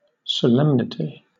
Ääntäminen
Southern England: IPA : /səˈlɛmnɪti/